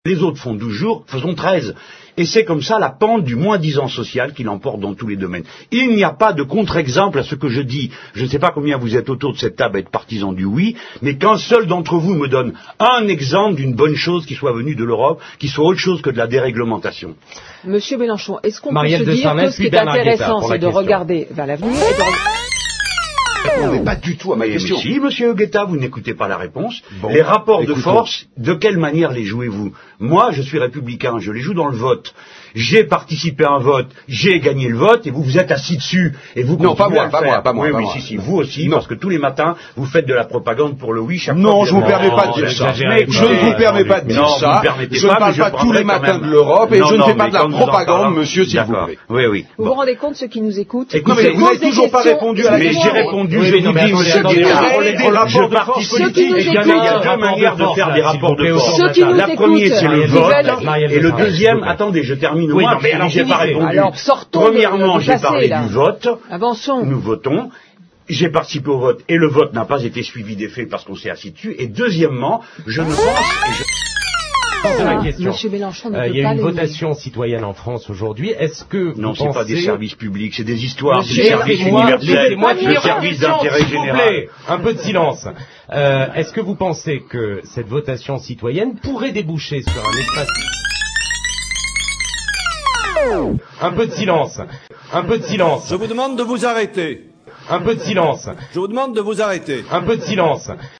S’ensuit un échange plutôt « musclé », comme on dit :
Alors il hurle (véridique) :